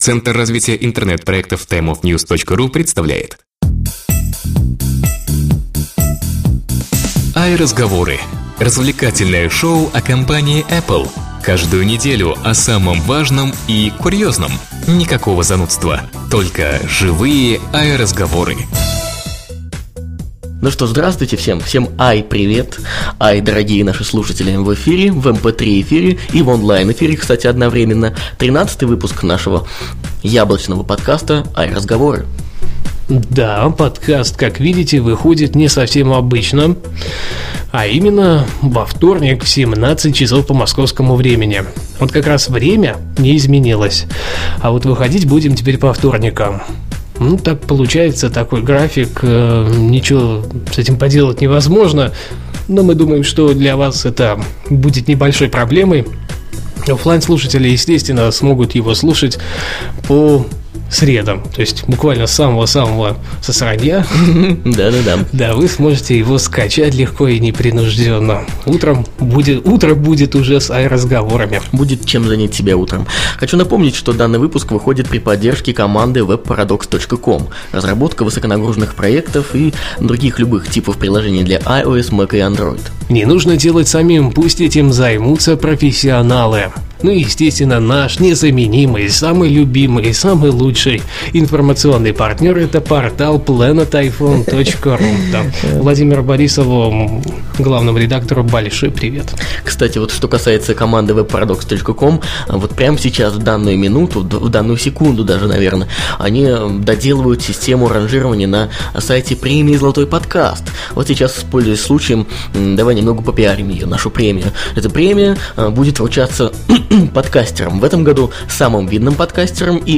АйРазговоры #13 "АйРазговоры" - еженедельный развлекательный подкаст о Apple
stereo Ведущие аудиошоу в свободной и непринужденной манере расскажут вам обо всех самых заметных событиях вокруг компании Apple за прошедшую неделю. Никакой начитки новостей, занудства, только живые "АйРазговоры".